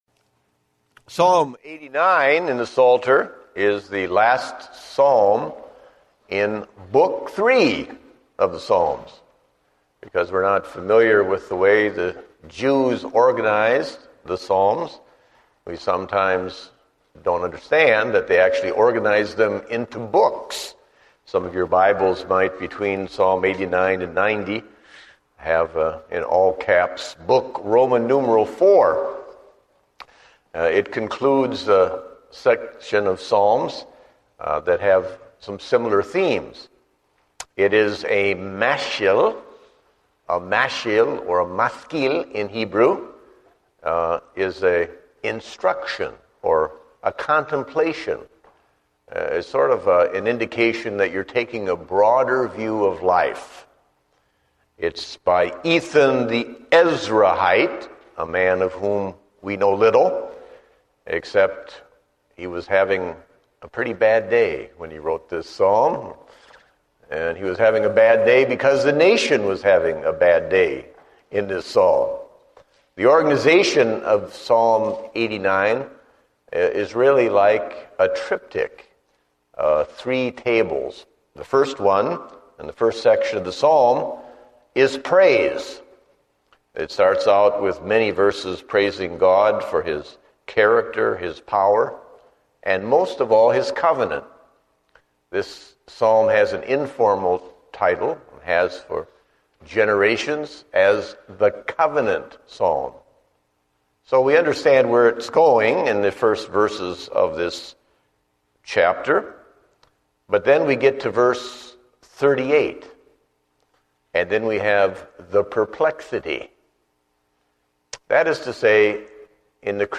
Date: August 15, 2010 (Evening Service)